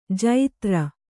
♪ jaitra